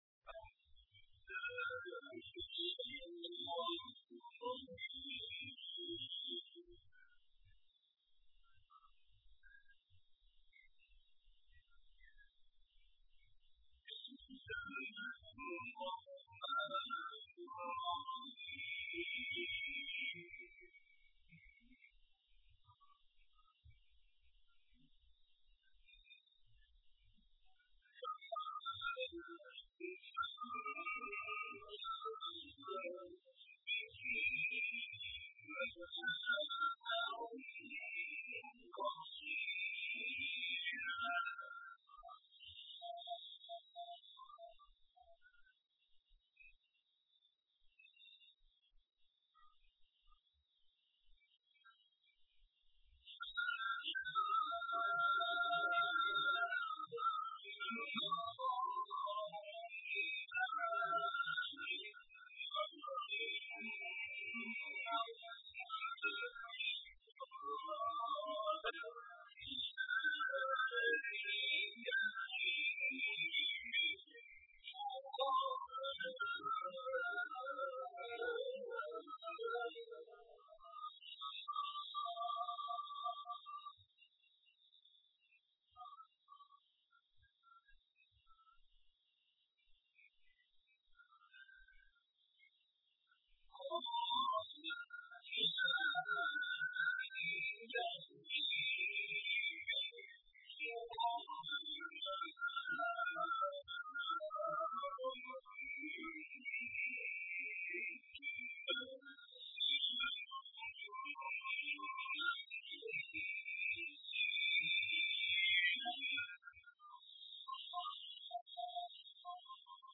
تلاوتی شاهکار از غلوش/ آیاتی از سوره‌های مریم، مطففین و علق+صوت
راغب مصطفی غلوش از قاریان نامدار جهان اسلام است که در این گزارش تلاوتی شاهکار از وی شامل آیاتی از سوره‌های مریم، مطففین و علق تقدیم می‌‌شود.